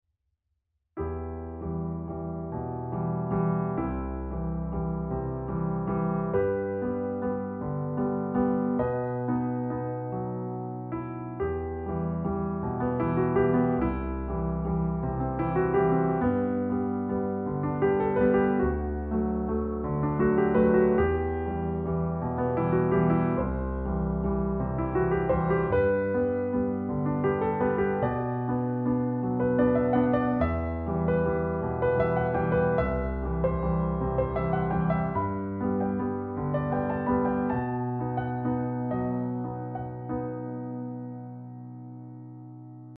Piano Music